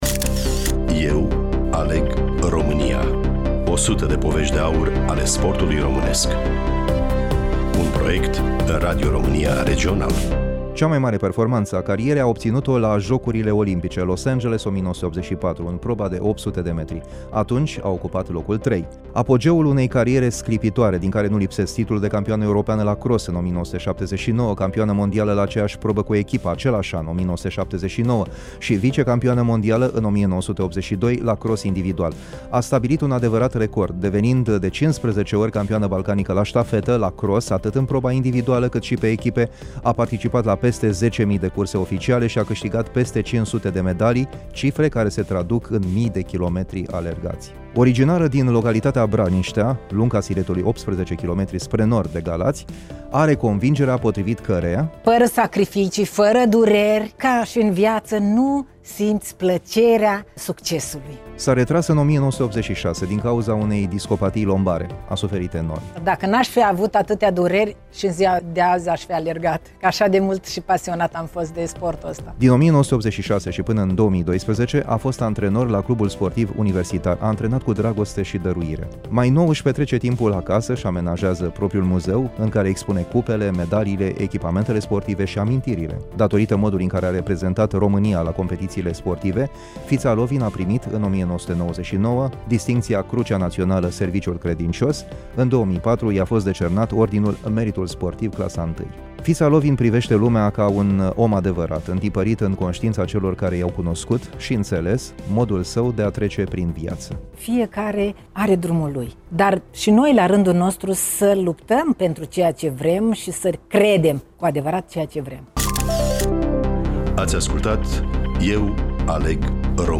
Studioul: Radio România Iaşi